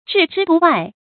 注音：ㄓㄧˋ ㄓㄧ ㄉㄨˋ ㄨㄞˋ
置之度外的讀法